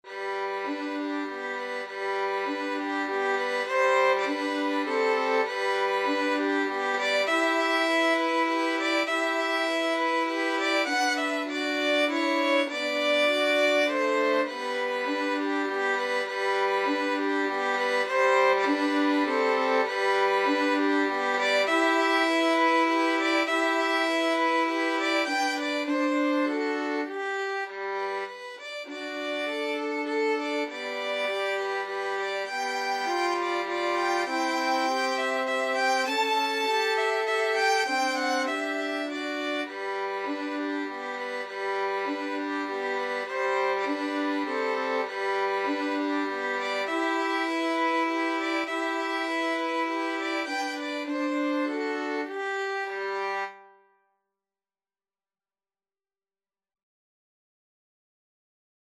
It is written in a simple waltz style.
Moderato
Violin Trio  (View more Easy Violin Trio Music)
brahms_waltz_3VLN.mp3